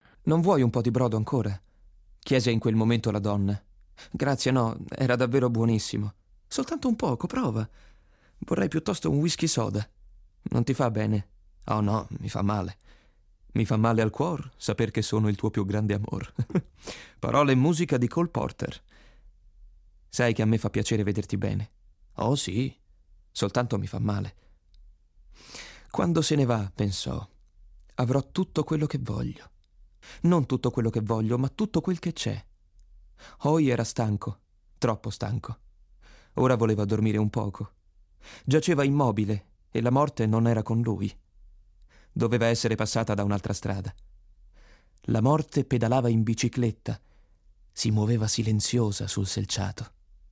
attore, doppiatore e speaker italiano dalla voce baritonale, che spazia dai toni limpidi e leggeri a quelli più profondi e malinconici, duttile ai diversi tipi di esigenze.
Sprechprobe: Sonstiges (Muttersprache):